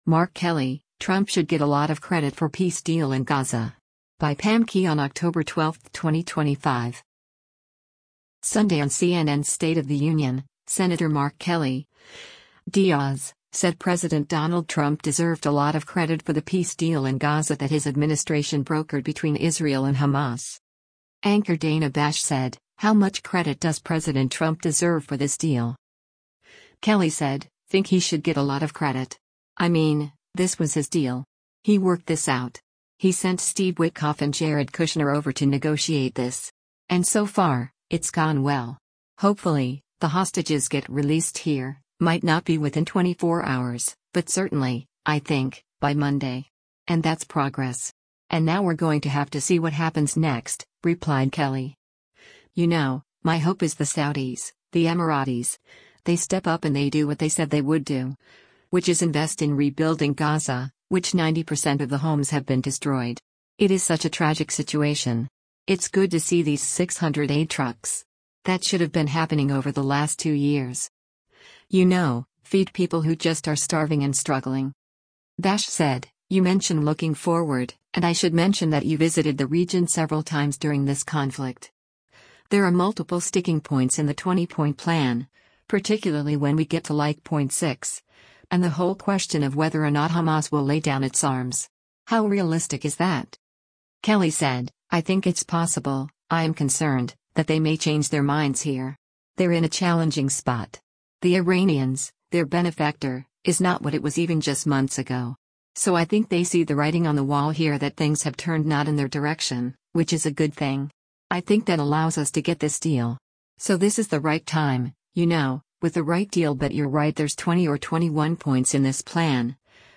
Sunday on CNN’s “State of the Union,” Sen. Mark Kelly (D-AZ) said President Donald Trump deserved a lot of credit for the peace deal in Gaza that his administration brokered between Israel and Hamas.